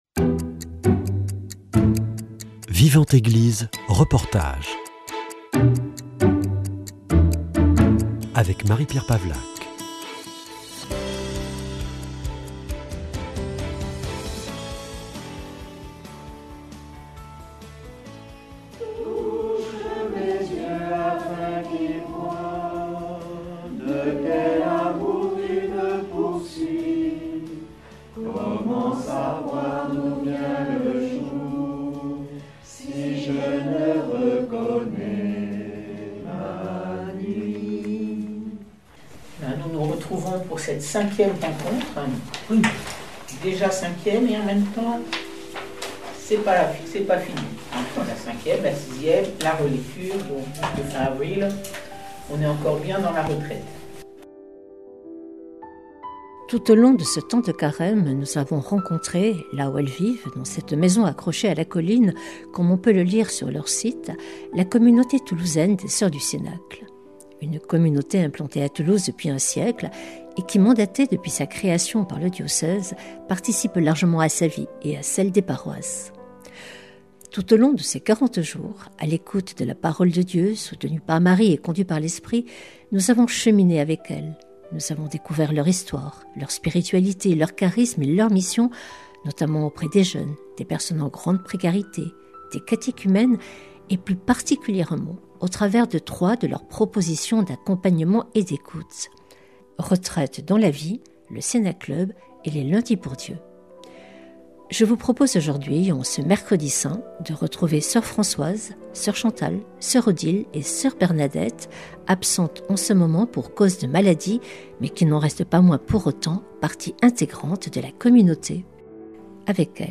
A quelques heures de ce Triduum Pascal, nous vous invitons à rejoindre la communauté toulousaine des sœurs du Cénacle. Tout au long de ce temps de carême et pour réaliser ce reportage, nous avons cheminé avec elles.